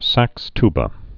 (săkstbə, -ty-)